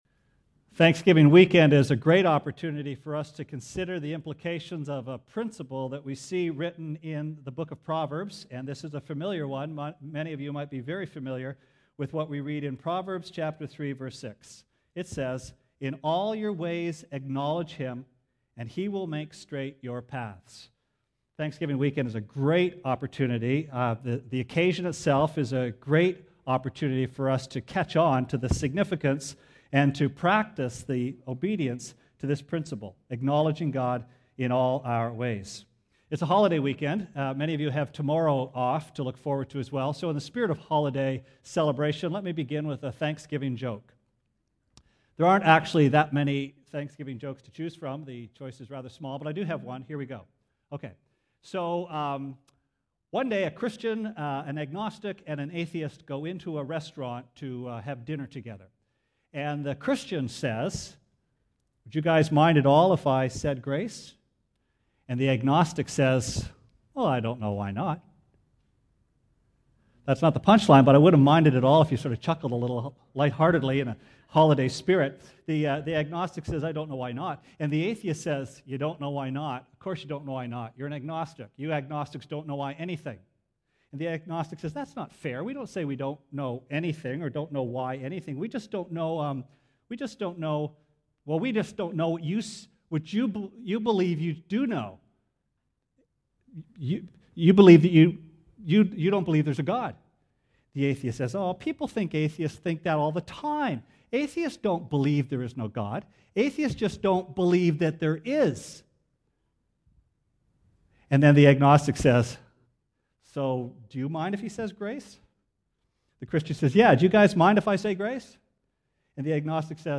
Sermon Archives Oct 12